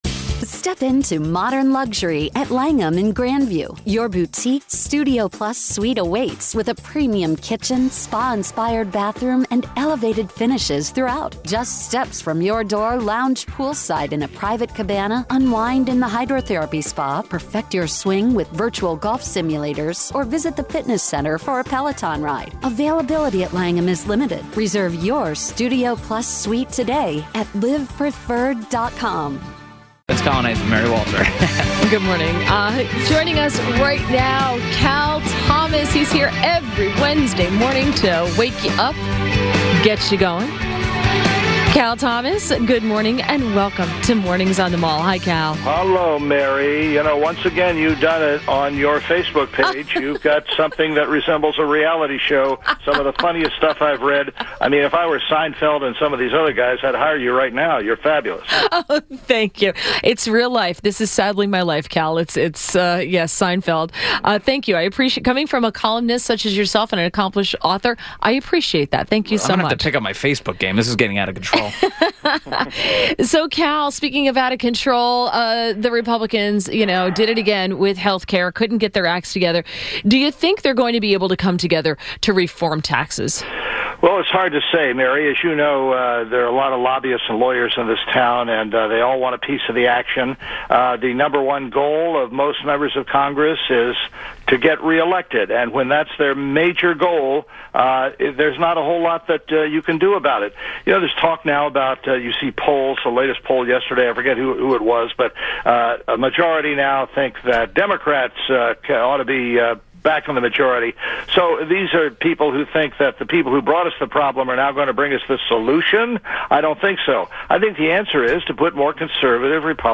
WMAL Interview - CAL THOMAS - 09.27.17